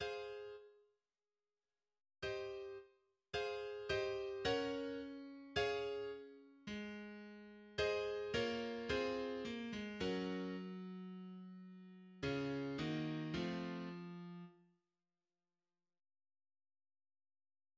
The second part of the movement begins with pizzicato strings introducing a new theme on the horns: